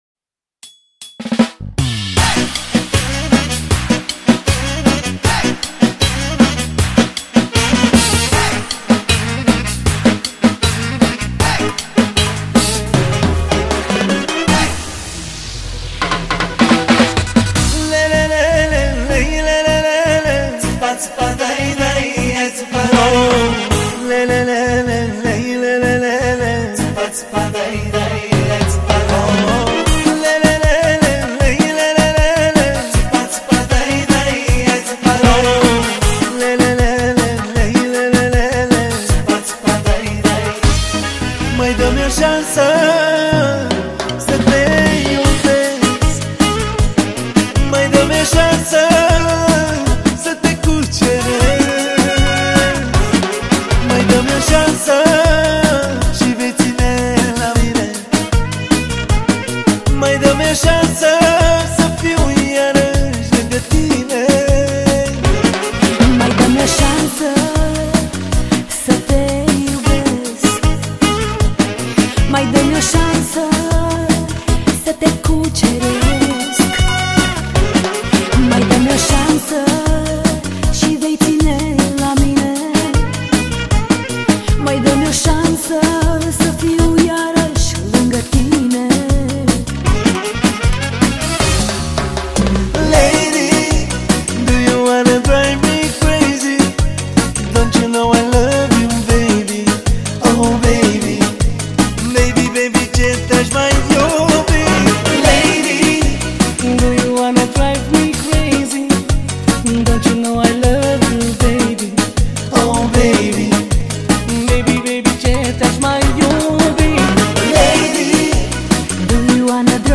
Data: 09.10.2024  Manele New-Live Hits: 0